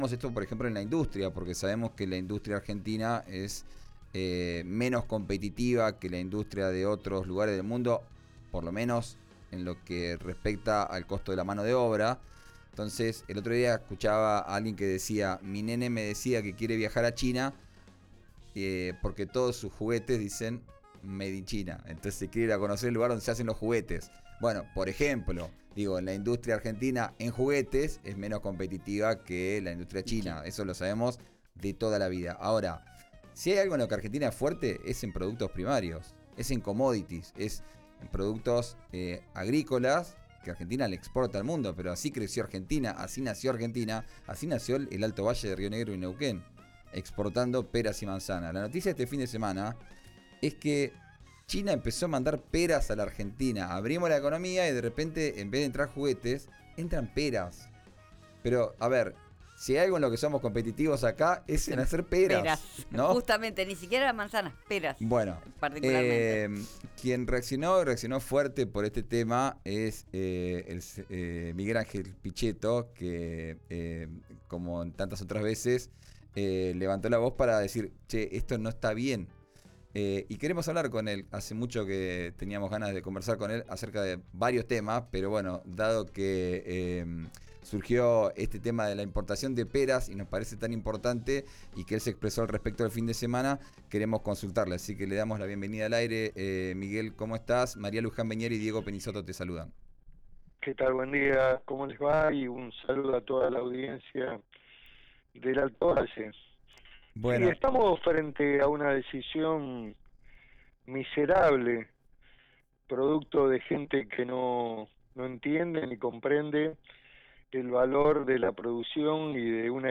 Escuchá la entrevista con el diputado nacional Miguel Pichetto en RÍO NEGRO RADIO